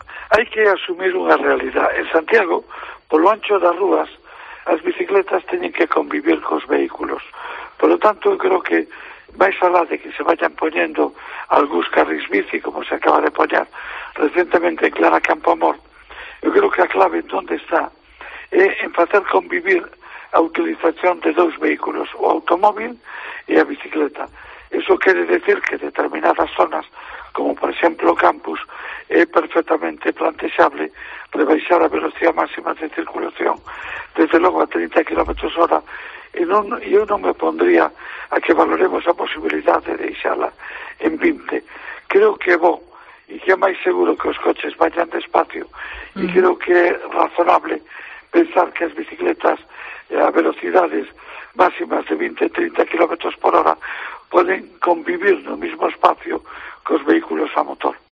Sánchez Bugallo, en declaraciones a COPE Santiago